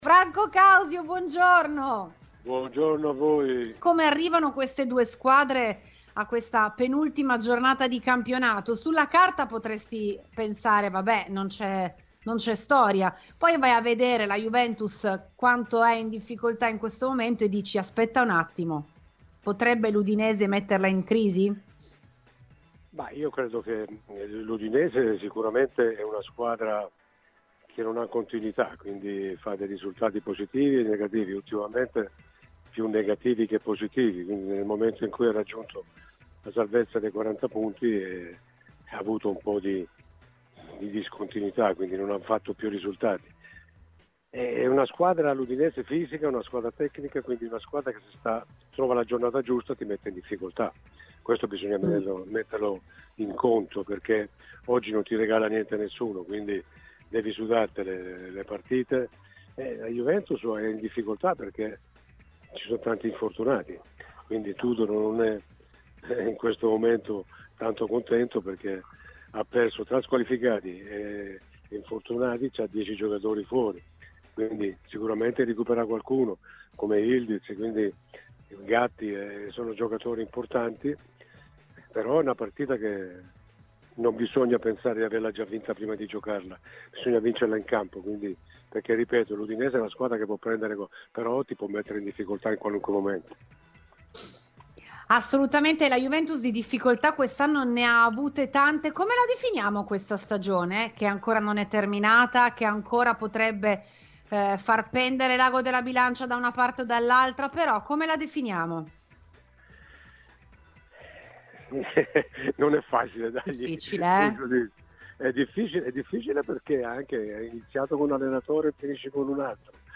Questa mattina lo abbiamo sentito durante la trasmissione RBN Cafè su Radiobianconera.